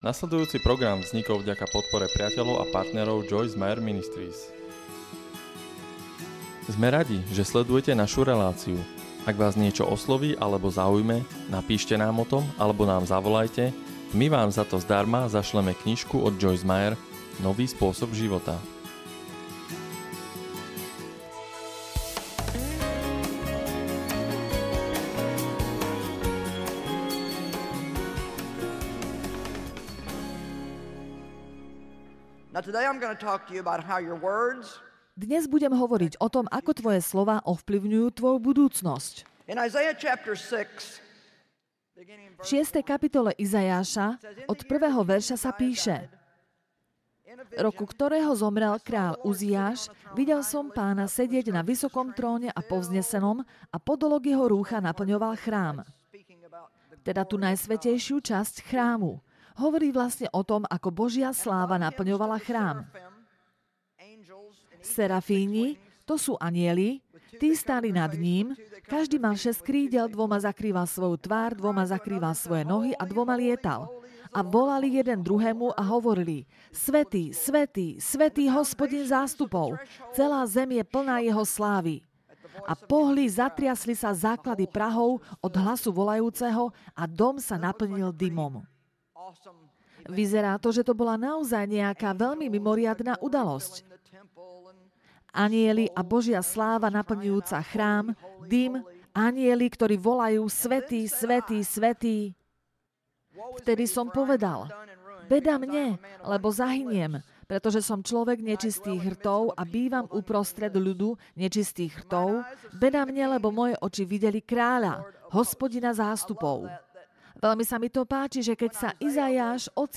Počúvajte povzbudzujúce posolstvá od najpredávanejšej autorky a učiteľky Biblie Joyce Meyer, ktoré vám pomôžu radovať sa z vášho každodenného života.